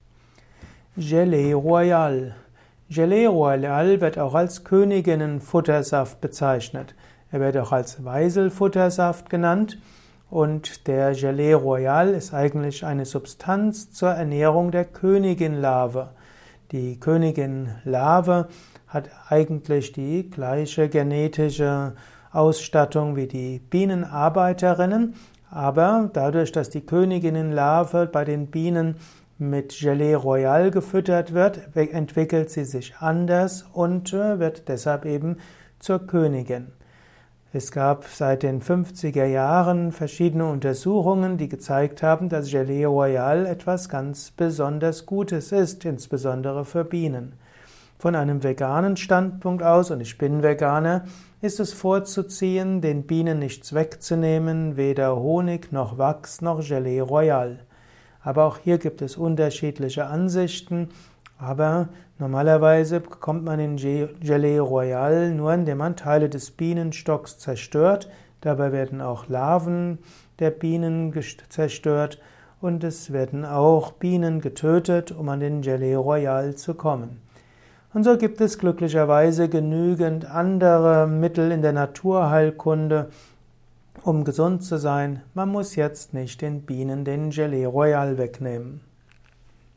Ein Kurzvortrag über Gelee Royale